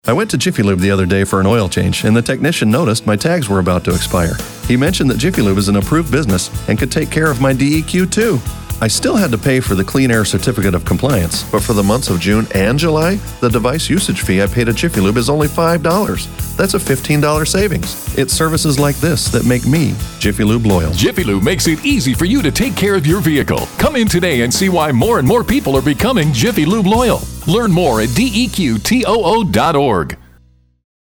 30 second ad